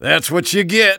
工程师
Engineer_specialcompleted11.wav